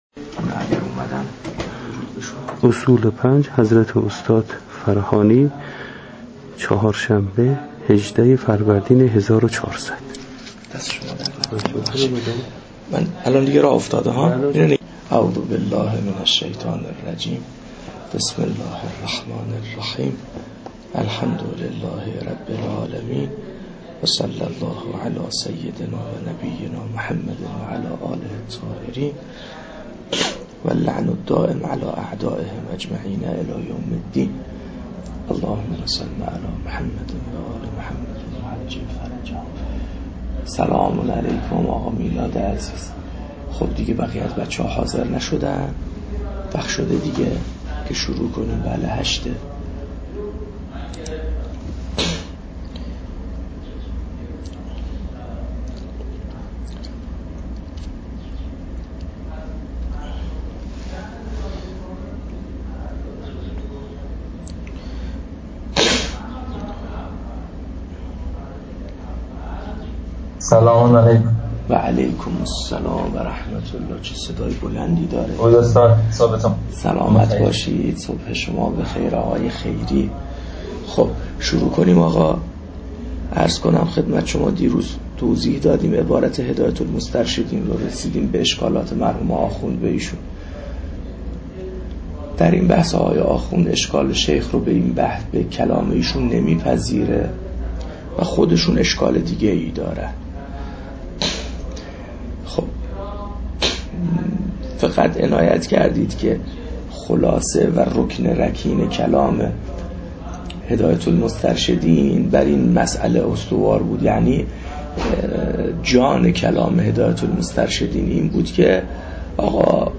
صوت دروس حوزوی- صُدا